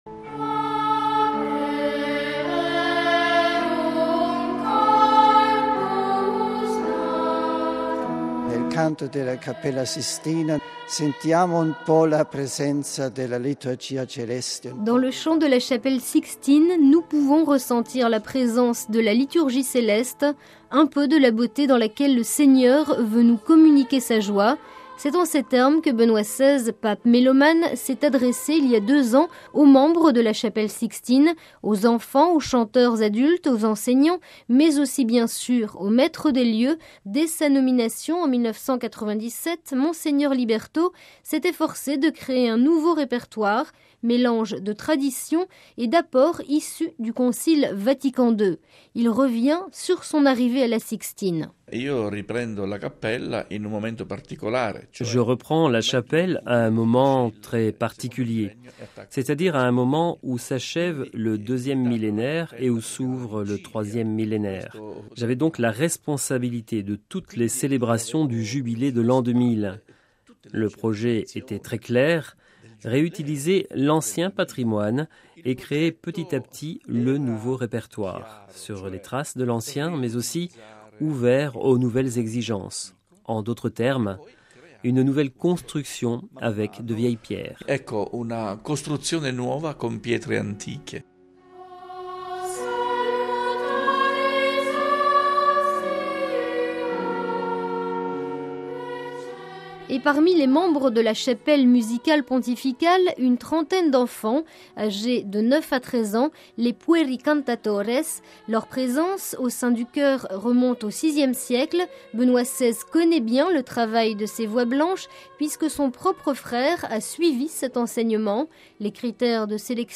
Dossier : les voix blanches de la chapelle Sixtine